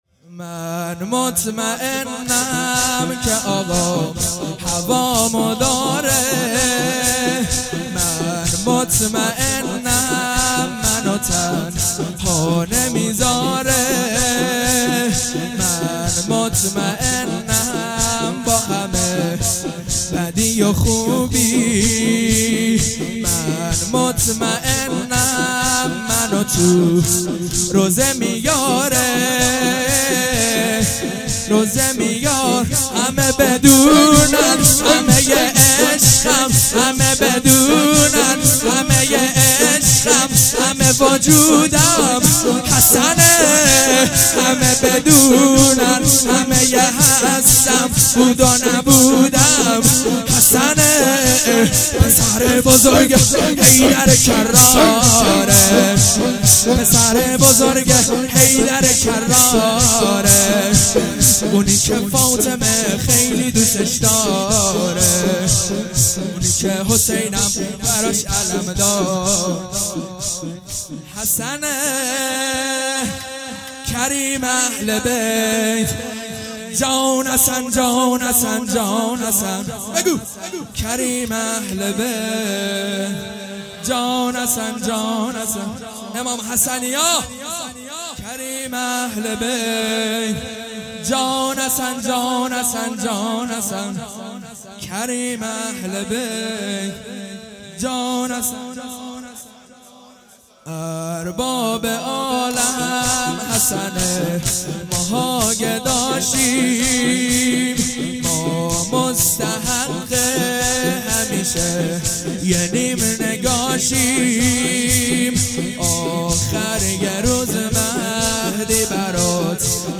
مراسم پیشواز محرم الرحرام 1438